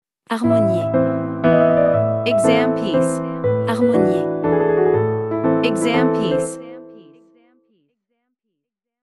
• 人声数拍
• 大师演奏范例
我们是钢琴练习教材专家